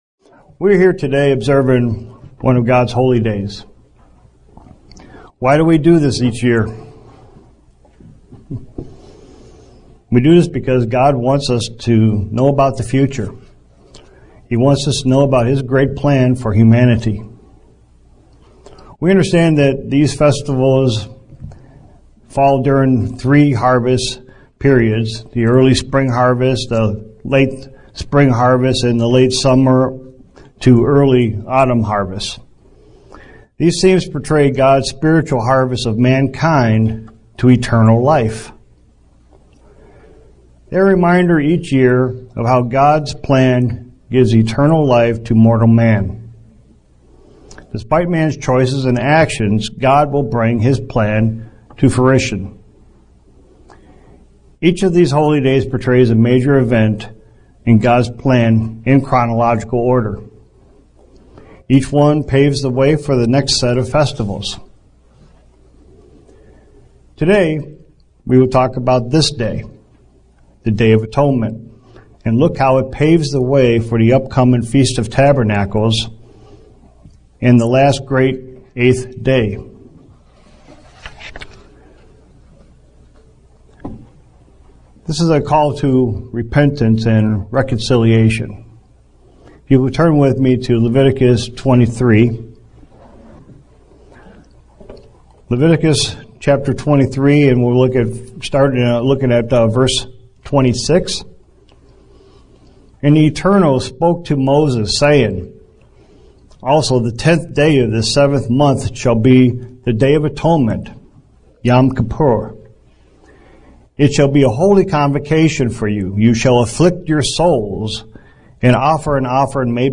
NO VIDEO UCG Sermon Studying the bible?